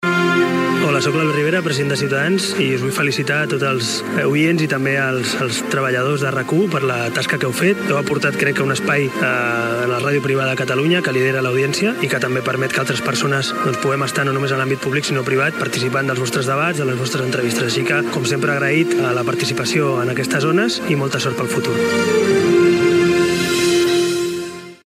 Felicitacions pels 15 anys de RAC 1.
Del polític de Ciudadanos Albert Rivera.